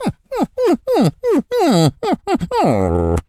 dog_whimper_cry_02.wav